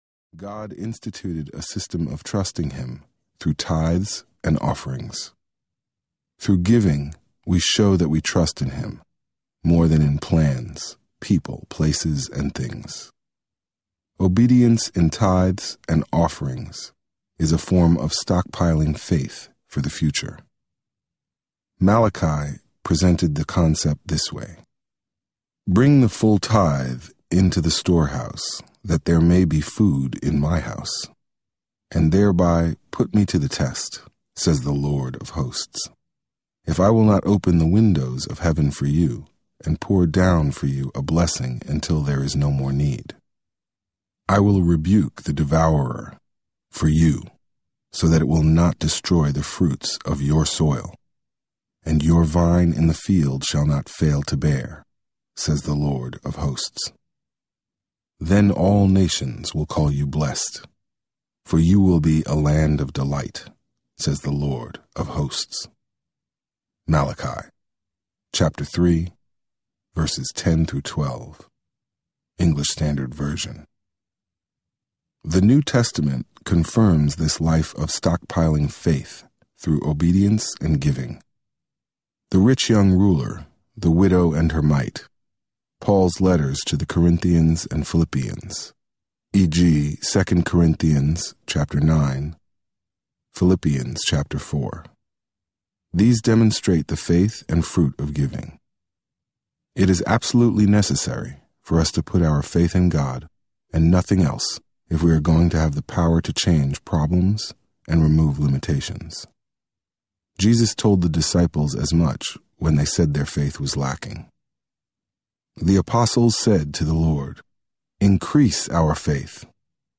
The Art of Joy Audiobook
Narrator